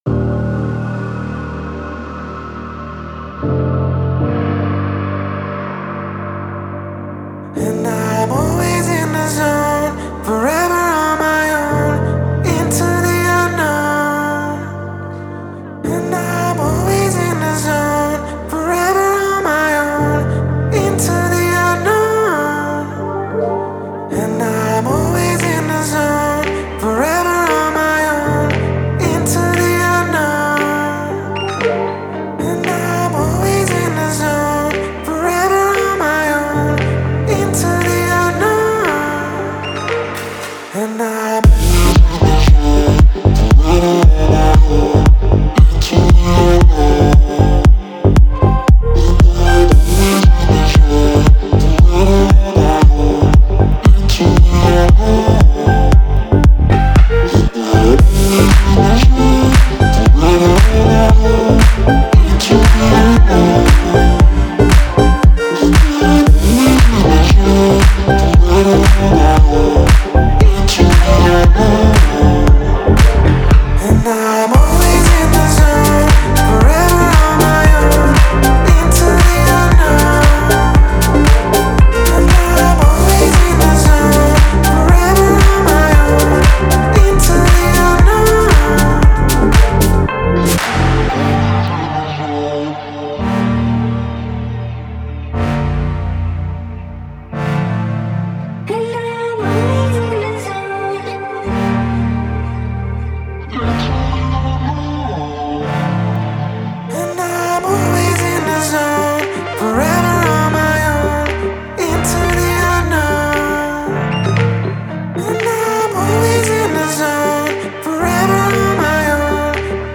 это динамичная EDM-композиция